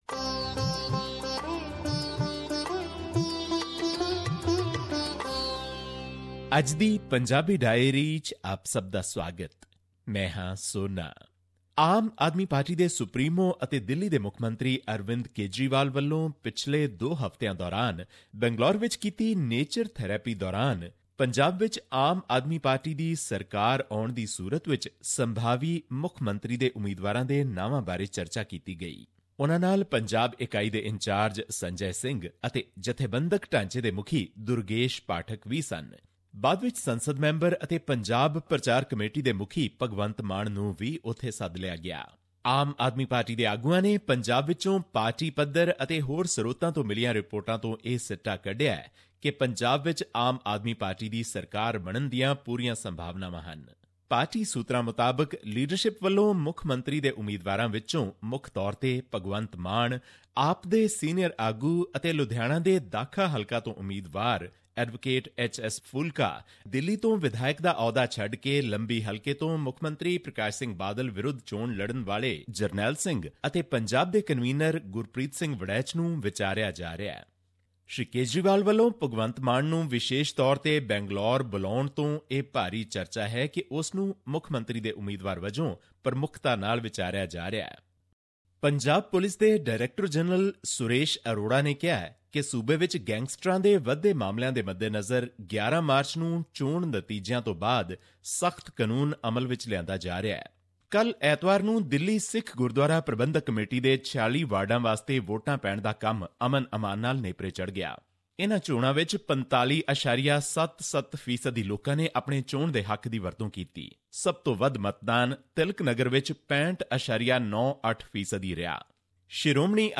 Here is the weekly report from Punjab